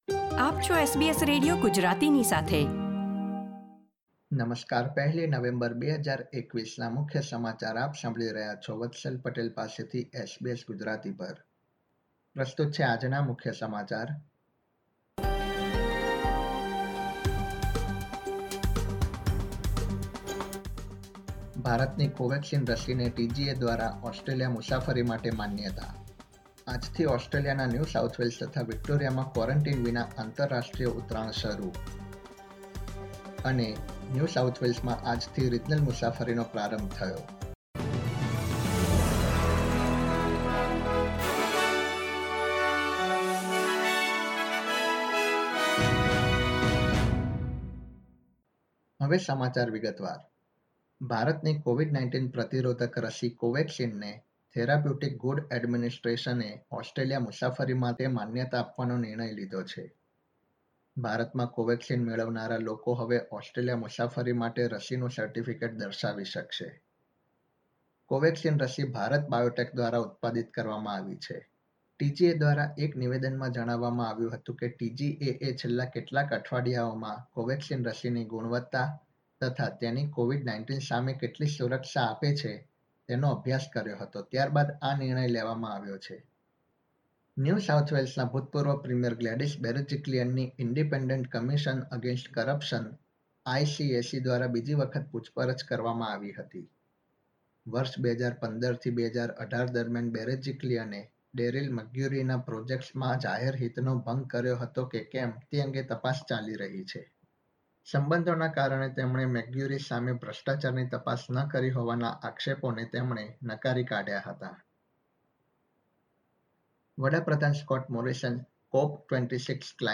SBS Gujarati News Bulletin 1 November 2021
gujarati_0111_newsbulletin.mp3